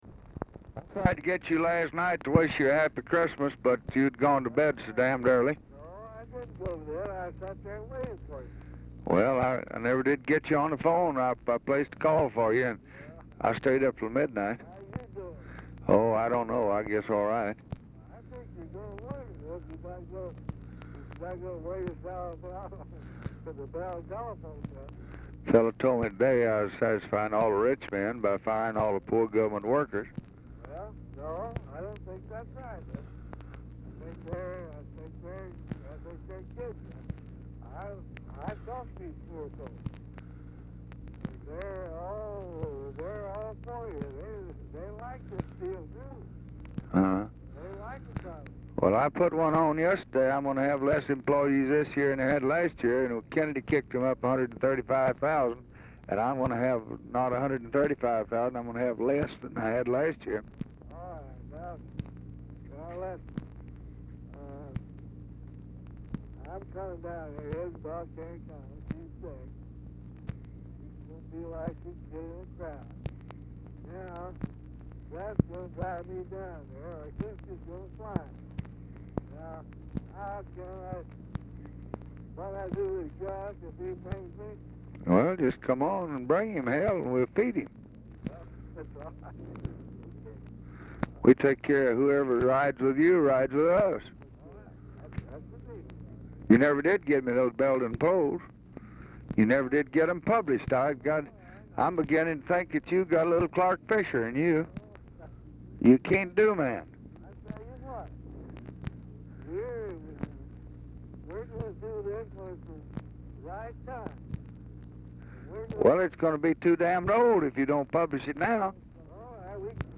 Telephone conversation
Format Dictation belt
LBJ Ranch, near Stonewall, Texas